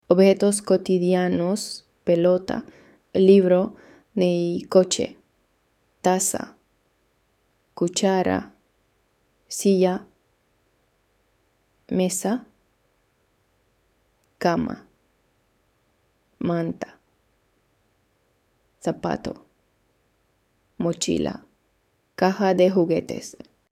Lesson 2